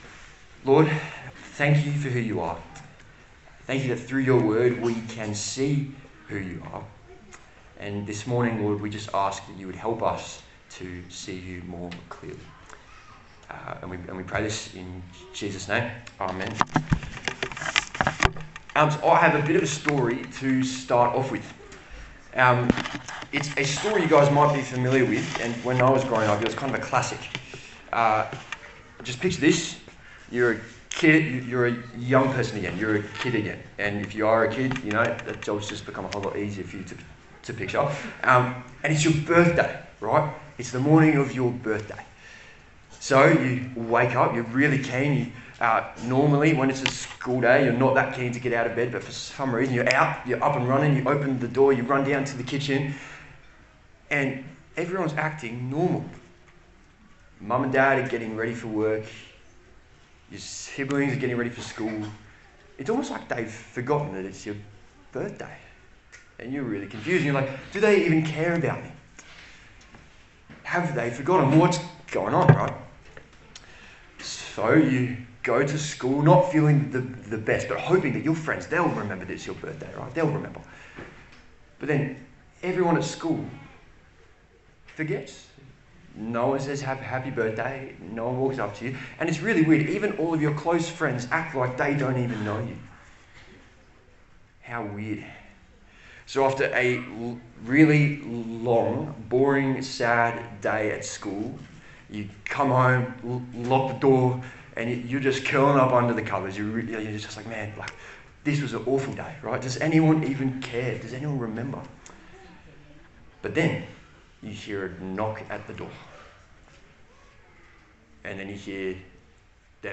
Service Type: Sunday Service A sermon on the letter of 2 Peter